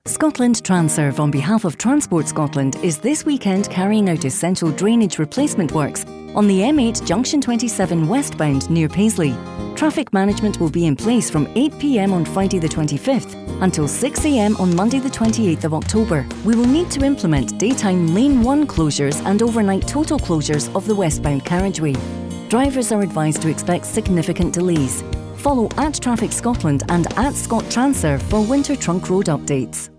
M8_Junction27_Radio_Adv.wav